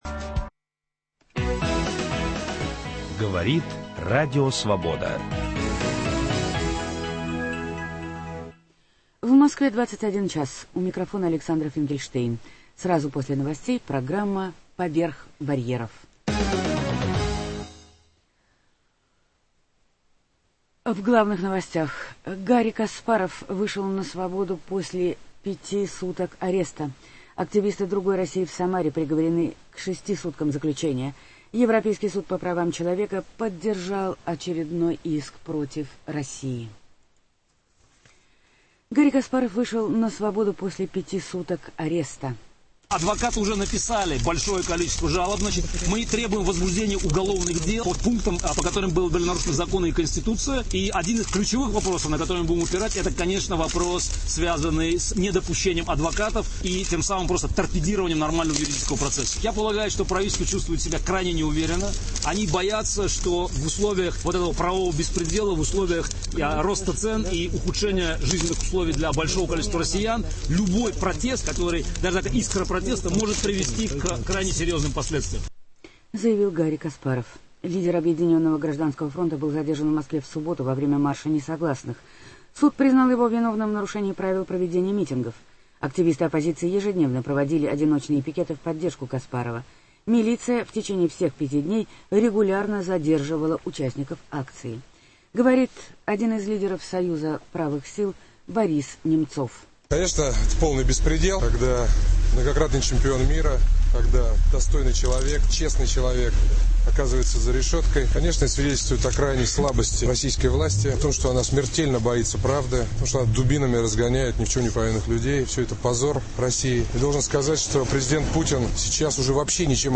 Интервью с Юрием Норштейном.